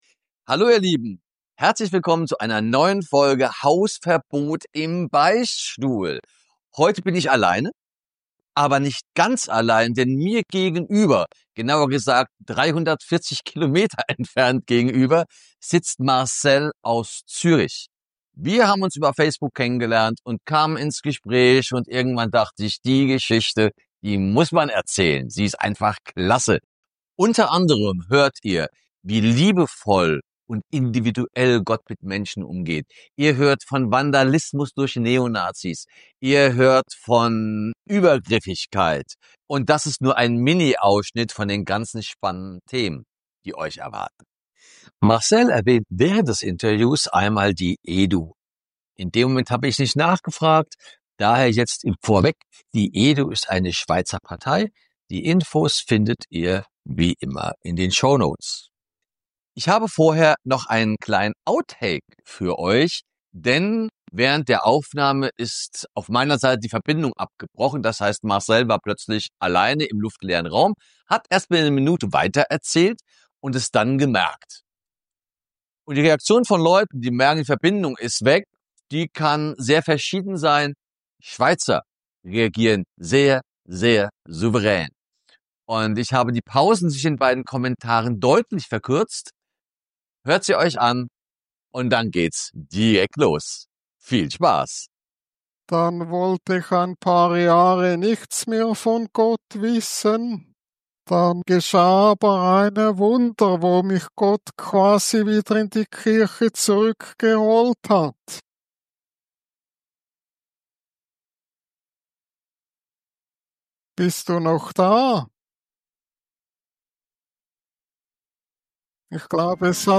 Anmerkung: Ihr hört Berner Dialekt!